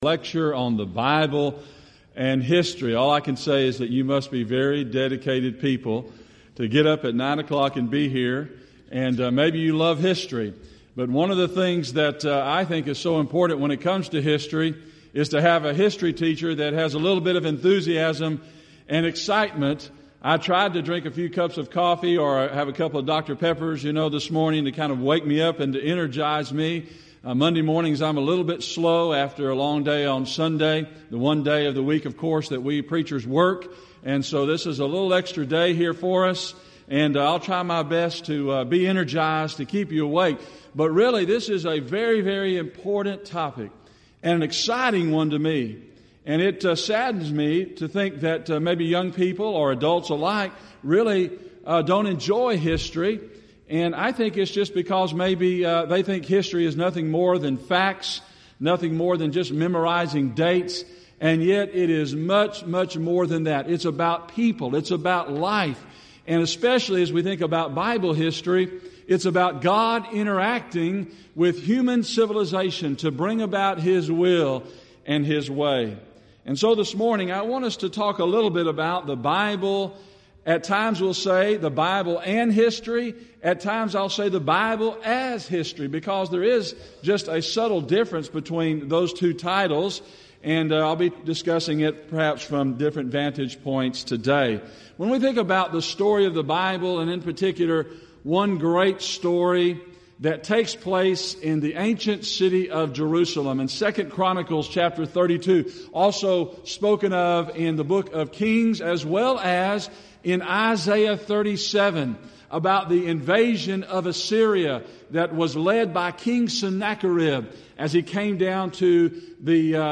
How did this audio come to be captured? Event: 30th Annual Southwest Bible Lectures